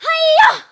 bea_atk_vo_03.ogg